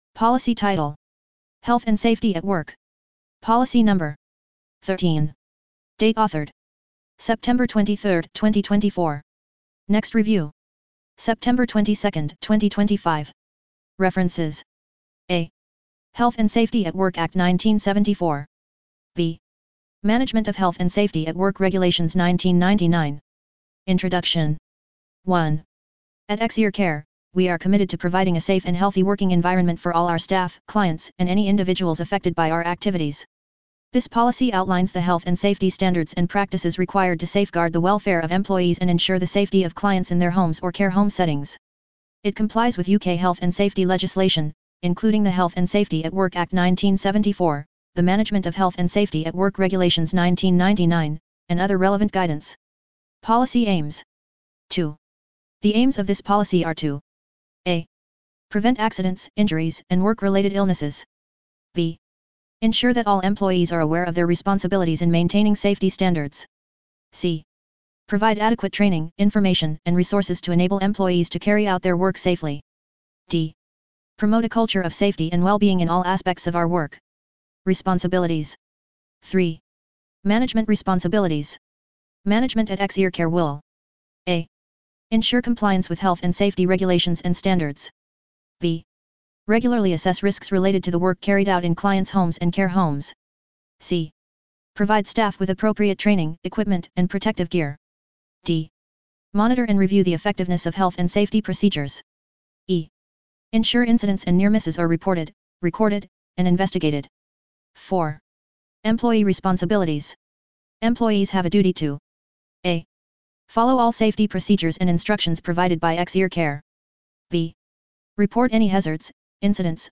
Narration of Health and Safety at Work Policy